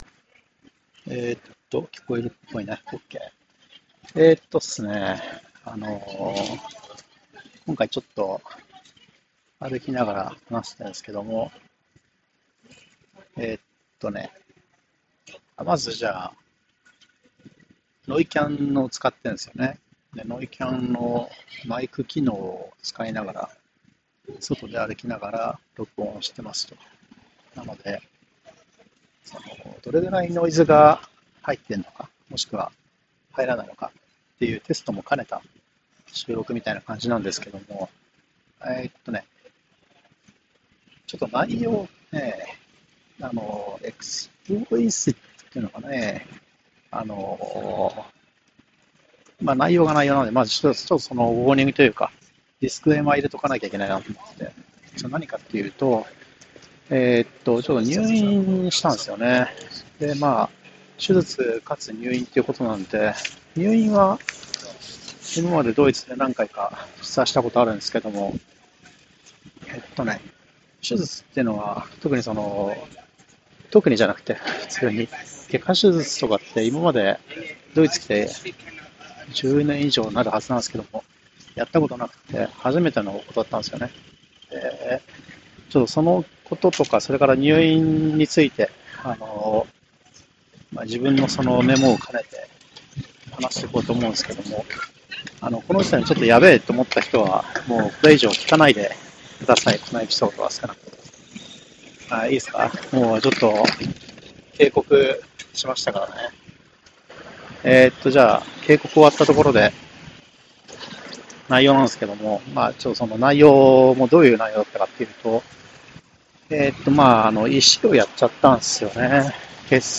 ノイキャンなヘッドフォンを使っての屋外での収録なのですが、内容は将来同じ様な状況で再度入院するときのために覚えておくことリストのようなものになりました。